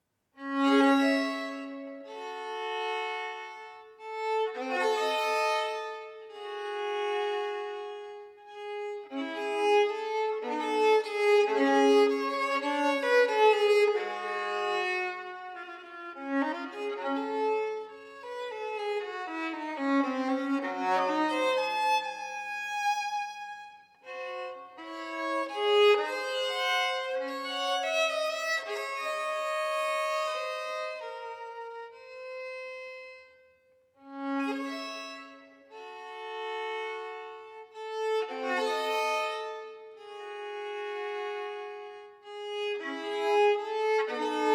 Violine
Barockvioline